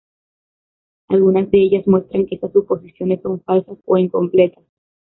e‧sas
/ˈesas/